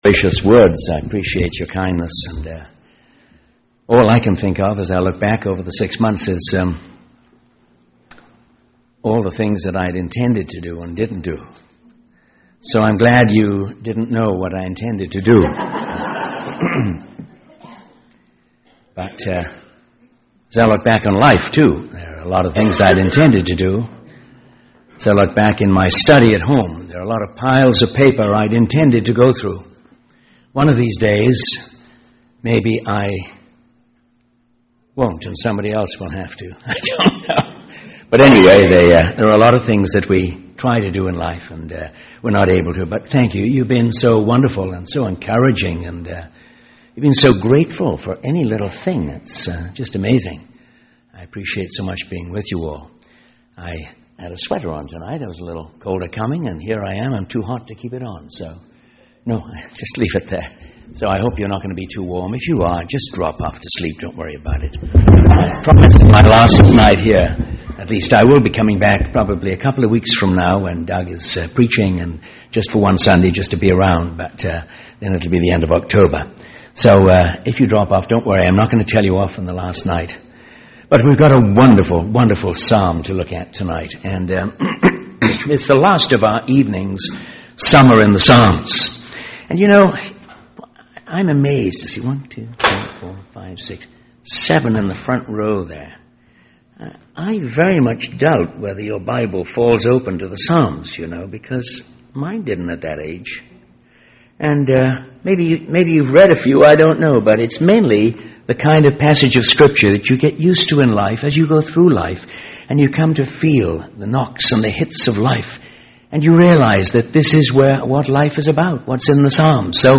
In this sermon, the speaker discusses the interpretation of Psalm 23, focusing on the first four verses.